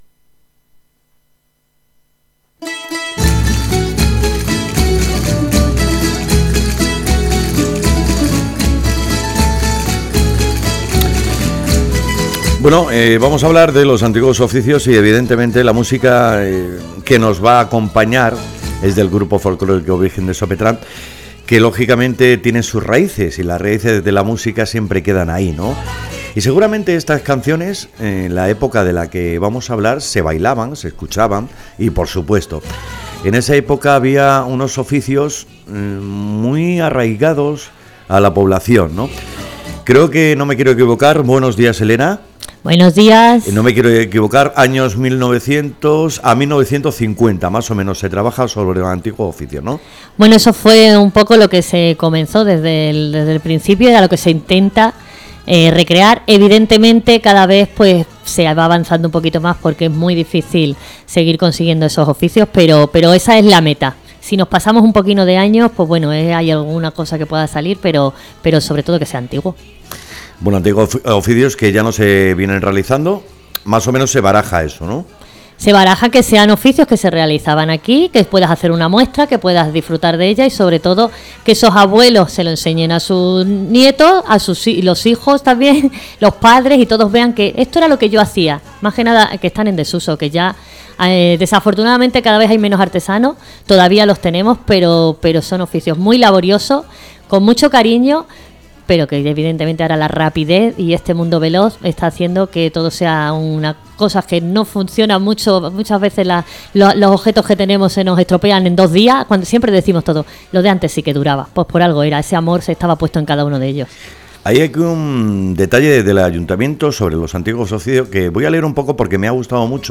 ENTREVISTA ONDA NORTE RADIO JARANDILLA